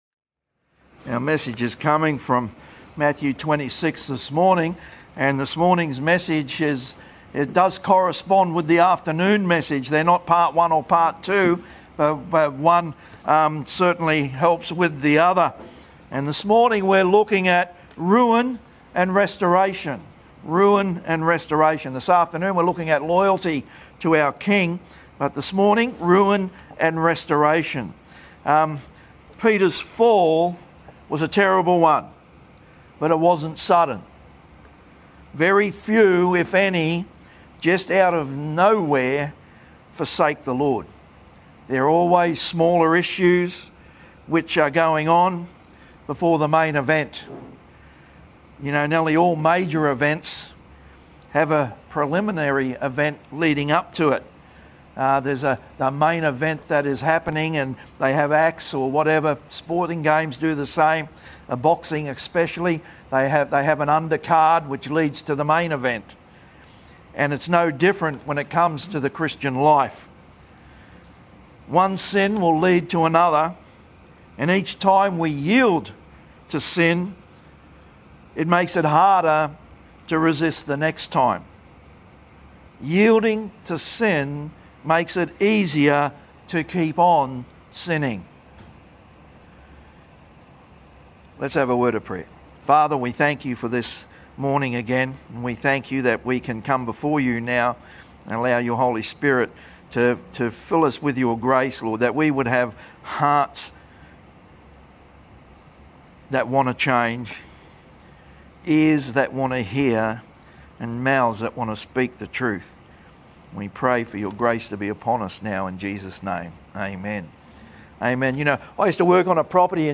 our AM sermon on Sunday the 22nd of February 2026 at the Gold Coast